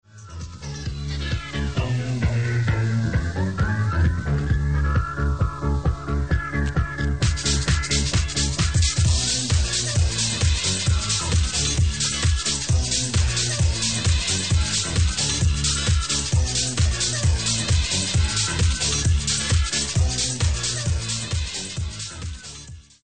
Funky house tune.